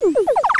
Spiraling up sound